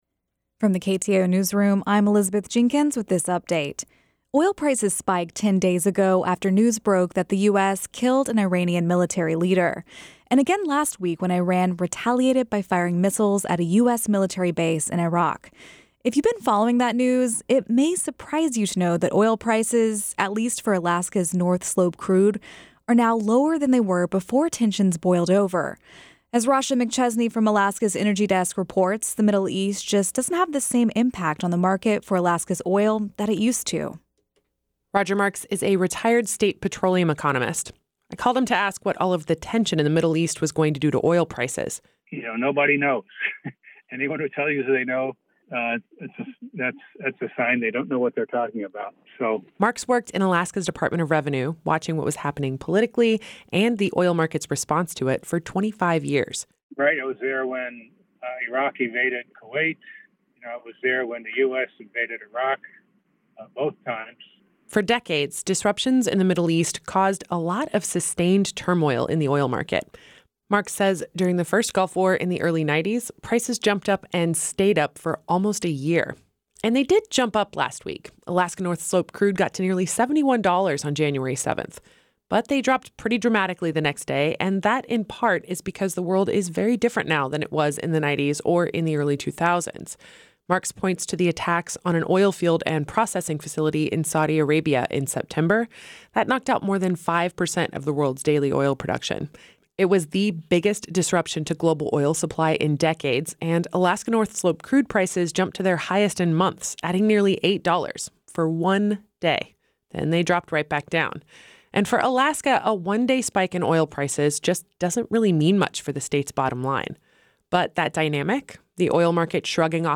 Newscast – Monday, Jan. 13, 2020